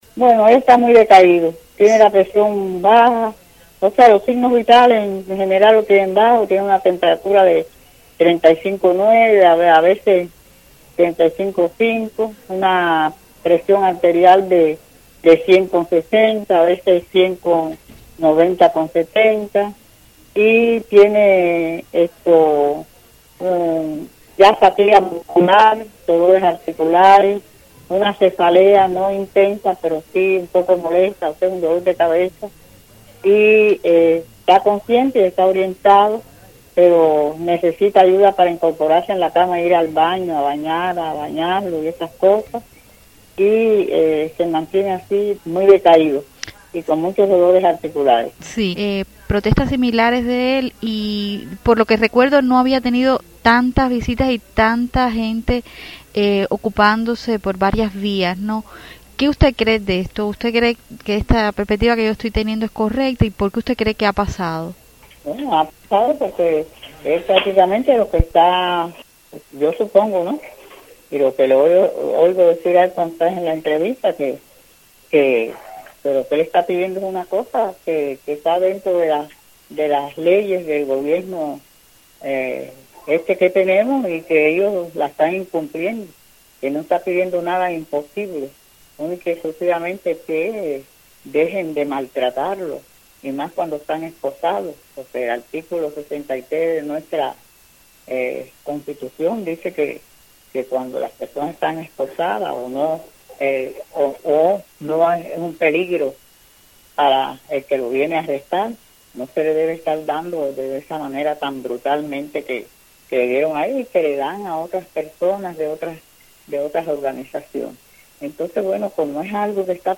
Fragmento de entrevista